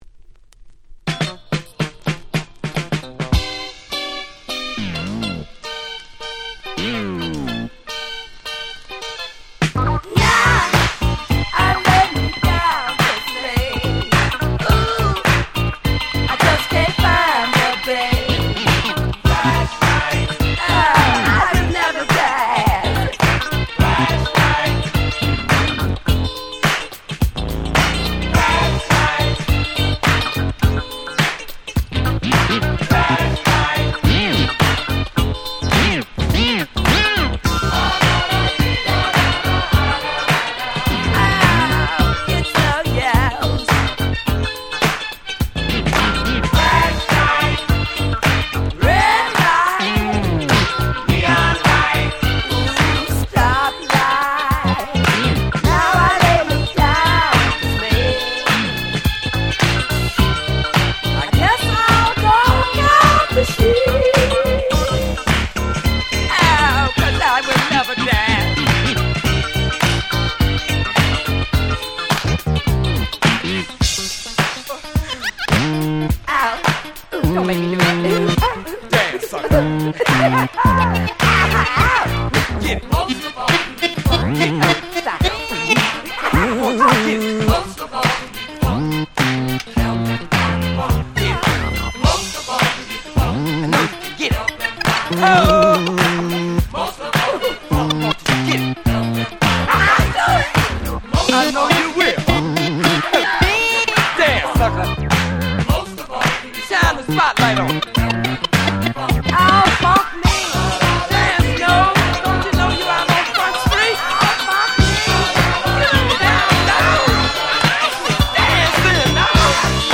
79' Super P-Funk Classics !!